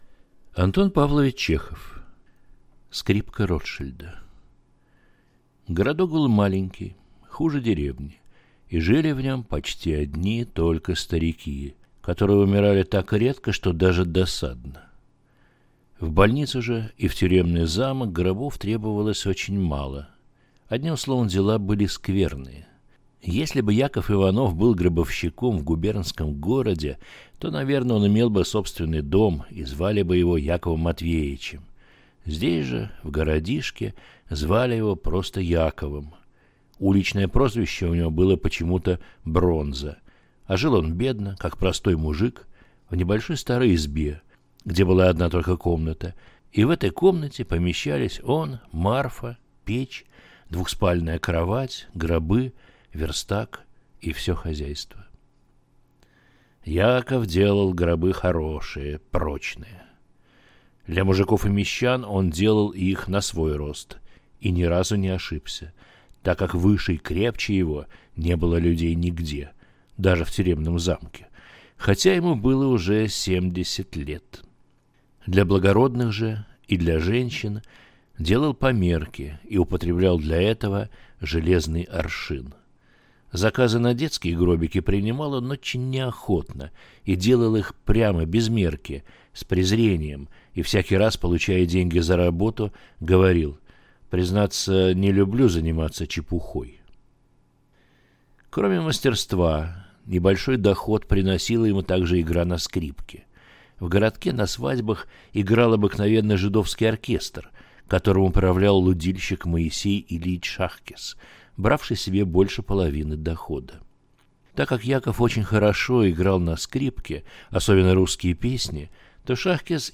Аудиокнига Скрипка Ротшильда | Библиотека аудиокниг